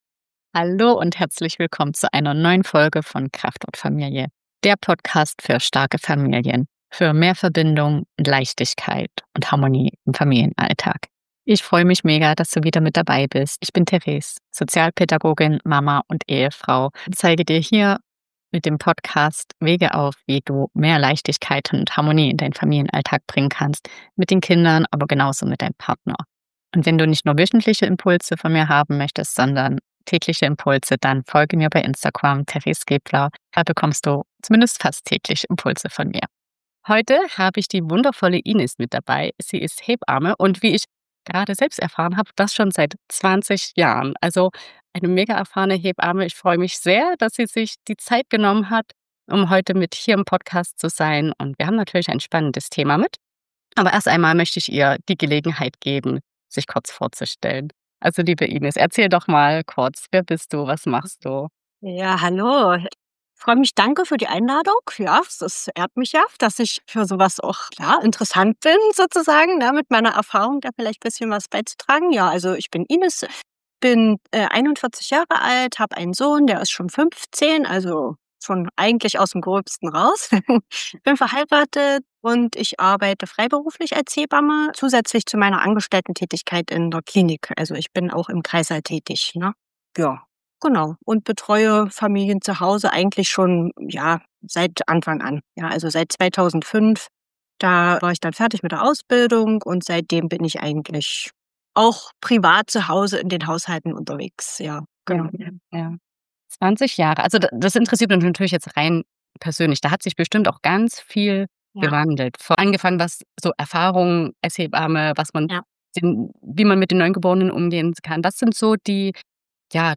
Warum Babys Tragkinder sind, Stillen nach Bedarf normal ist und Nähe kein „Verwöhnen“ bedeutet. Wir reden über die ersten 14 Tage ohne Leistungsdruck, wie Partner und Hebamme wirklich entlasten können – und wie du mit Ratschlägen, Vergleichen und Social-Media-Glanzbildern souverän umgehst. Eine ehrliche Folge für alle, die weniger Perfektion und mehr Bauchgefühl wollen.